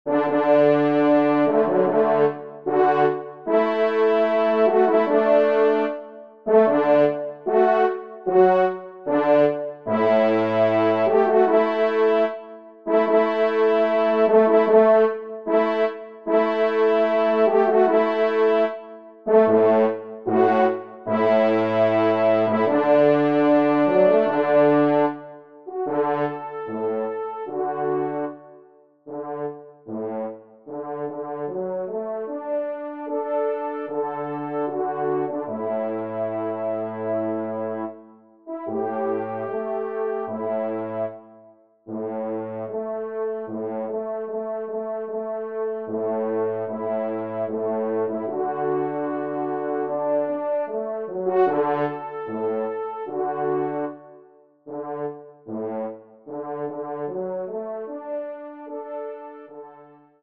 Genre :  Divertissement pour Trompes ou Cors en Ré
4e Trompe